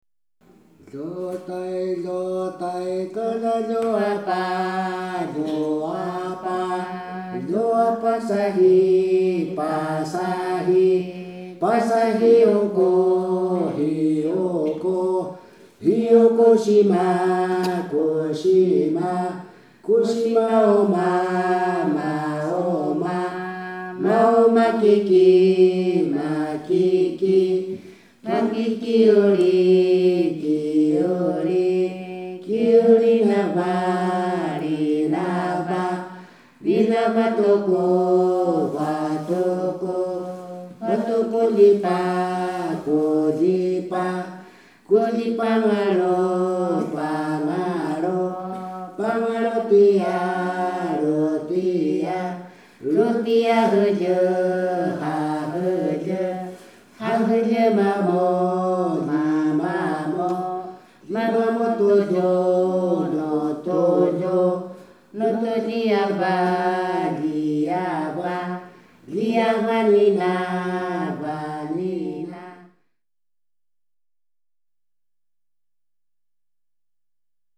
排湾童谣曲调简 洁生动，但歌词隐喻颇深，必须反复推敲始能知晓其中另有所指。